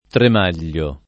tremaglio [ trem # l’l’o ]